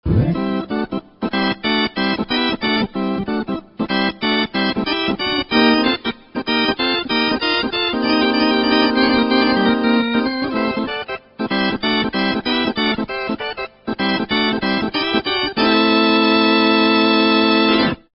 Extrait orgue
L'orgue électronique.
orgue_electrique.mp3